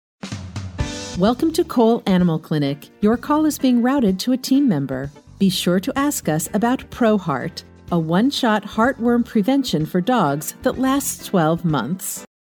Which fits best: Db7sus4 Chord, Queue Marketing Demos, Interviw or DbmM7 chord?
Queue Marketing Demos